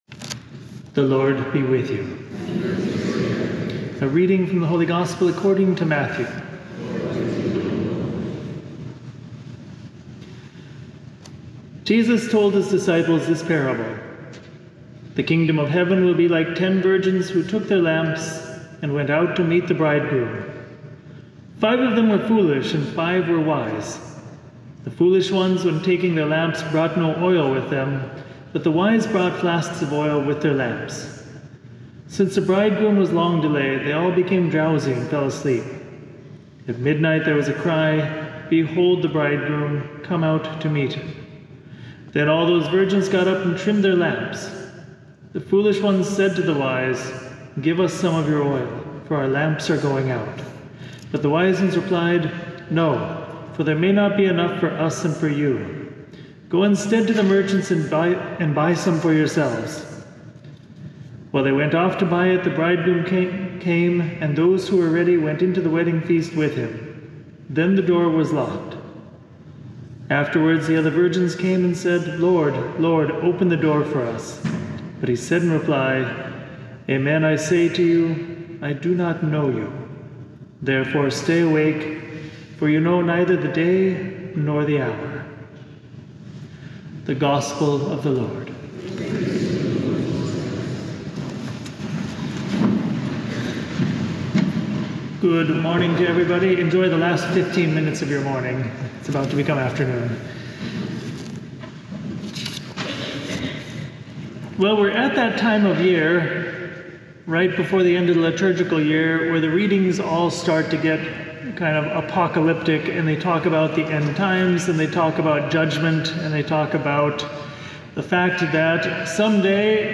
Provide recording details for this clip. for the 11th Sunday in Ordinary Time at St. Patrick Church in Armonk, NY.